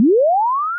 Swoosh Transition
Swoosh Transition is a free ui/ux sound effect available for download in MP3 format.
035_swoosh_transition.mp3